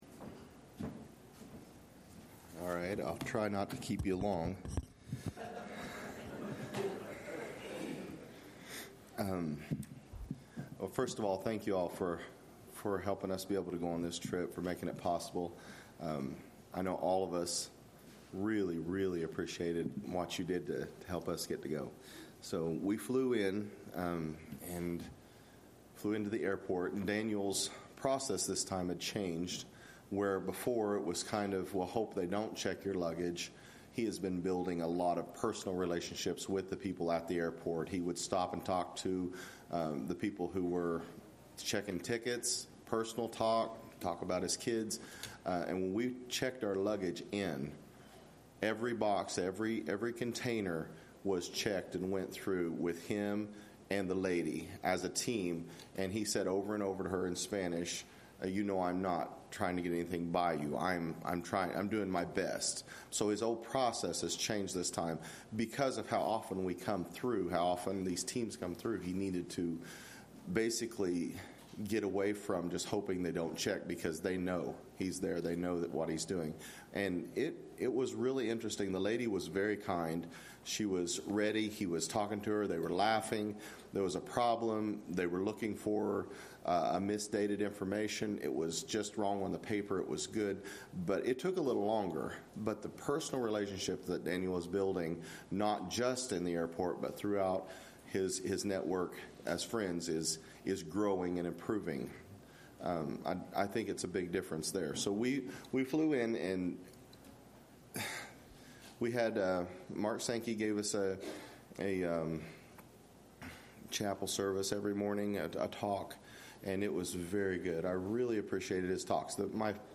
Report from those who went to Honduras as part of the EFM Medical Brigade in October 2024